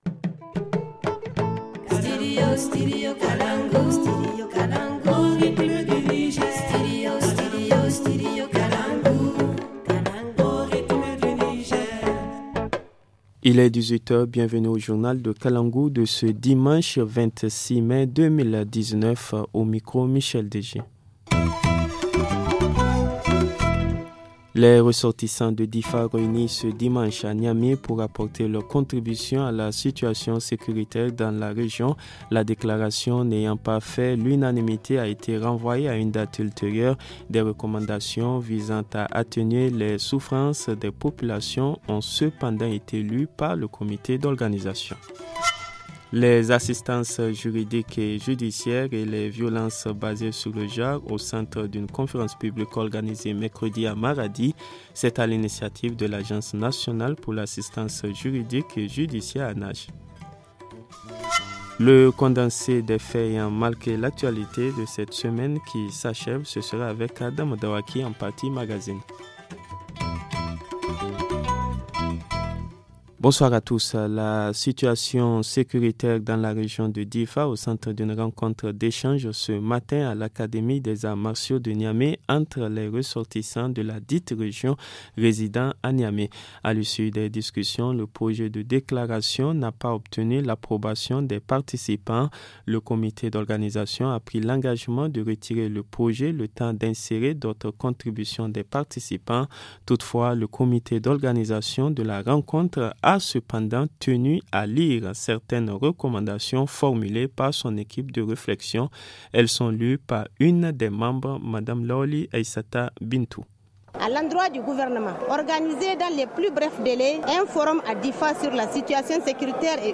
Le journal du 26 mai 2019 - Studio Kalangou - Au rythme du Niger